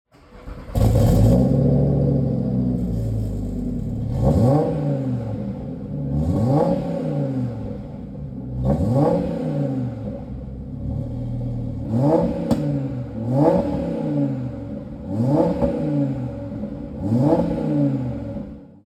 Listen to the 5-Cylinder Fury!
• RS Sports Exhaust (£1,000)
Audi-TTRS-nardo-grey-revs.mp3